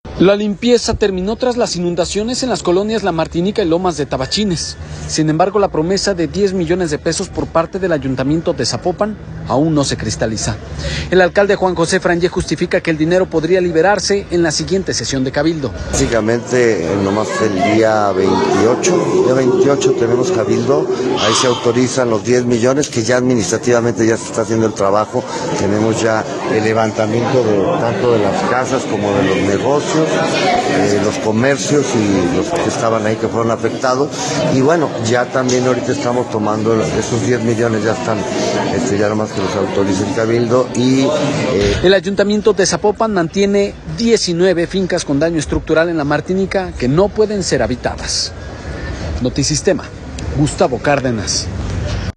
La limpieza terminó tras las inundaciones en las colonias La Martinica y Lomas de Tabachines, sin embargo, la promesa de 10 millones de pesos por parte del Ayuntamiento de Zapopan aún no se cristaliza. El alcalde, Juan José Frangie, justifica que el dinero podría liberarse en la siguiente sesión de Cabildo.